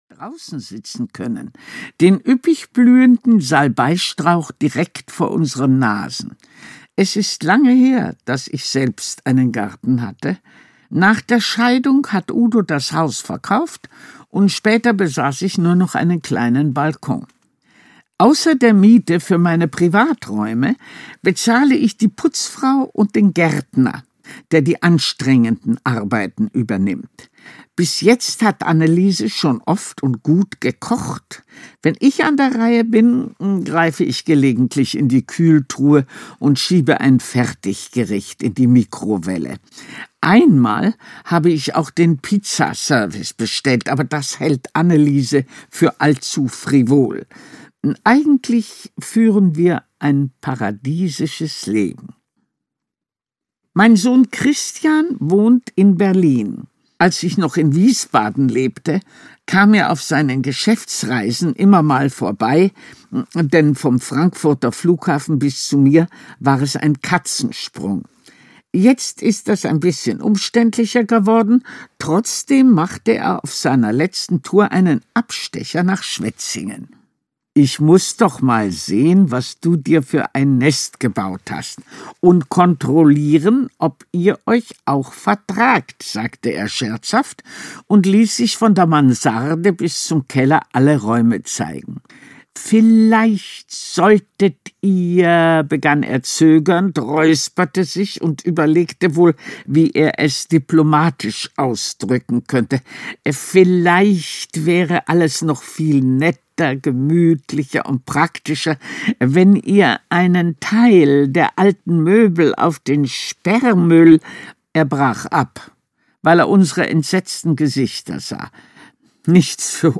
Ladylike - Ingrid Noll - Hörbuch